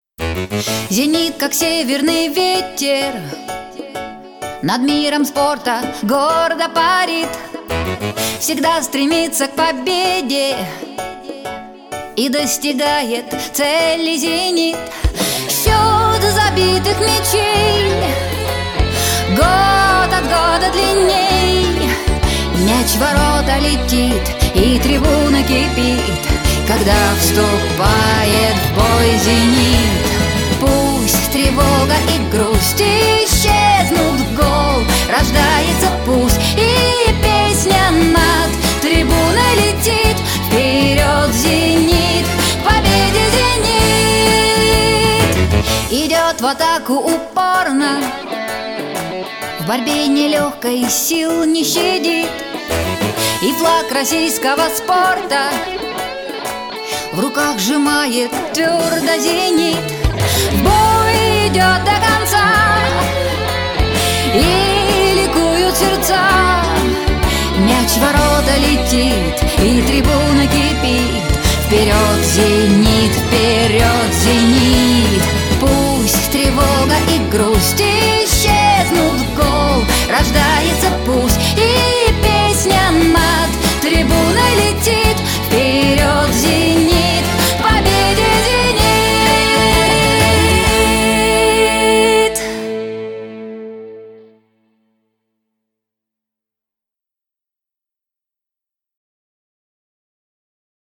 Жанр: Pop
Стиль: Europop